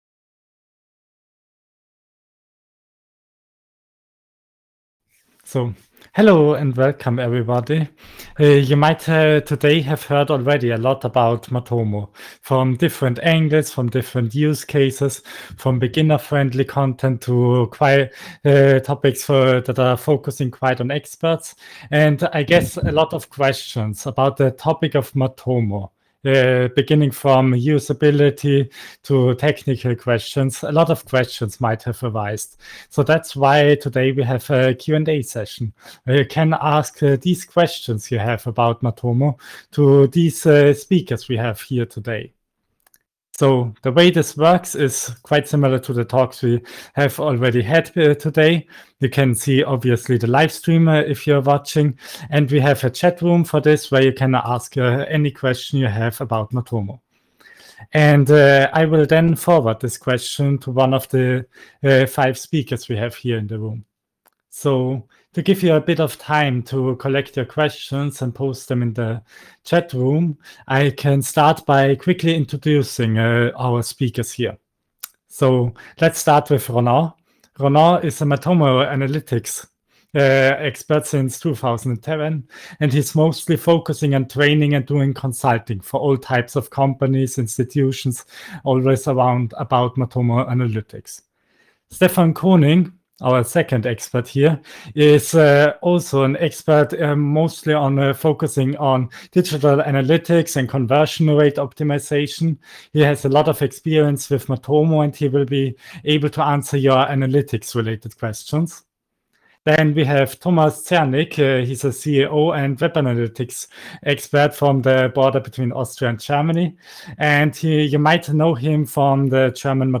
Several experts answer questions from users